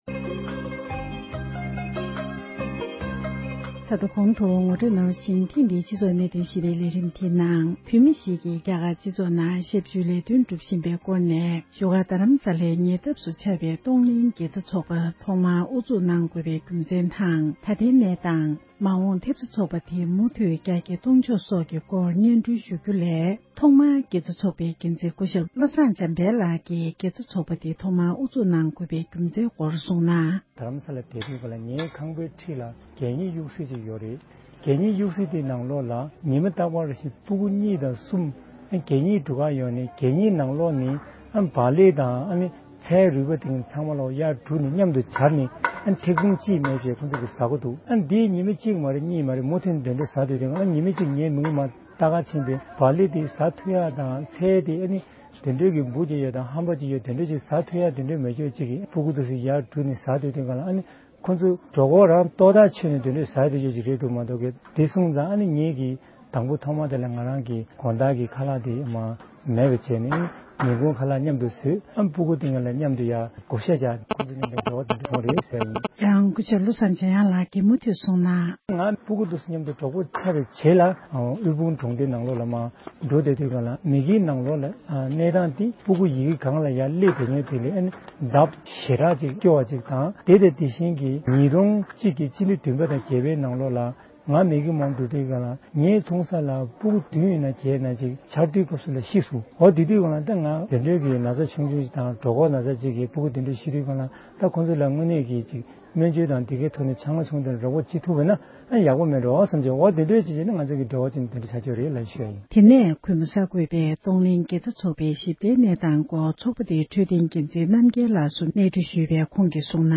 འབྲེལ་ཡོད་མི་སྣར་གནས་འདྲི་ཞུས་ཏེ་གནས་ཚུལ་ཕྱོགས་སྒྲིགས་དང་སྙན་སྒྲོན་ཞུས་པ་ཞིག་གསན་རོགས་ཞུ༎